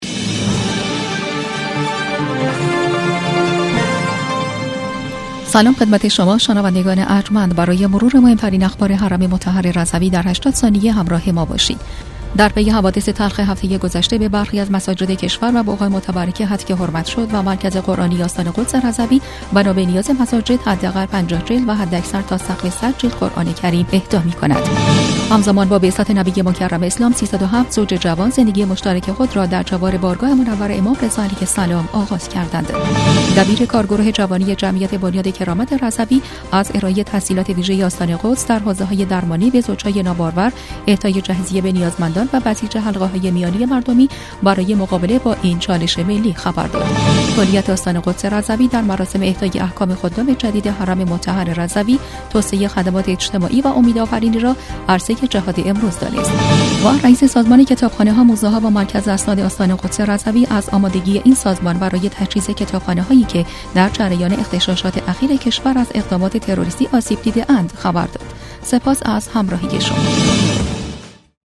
برچسب ها: رادیو رادیو رضوی بسته خبری رادیو رضوی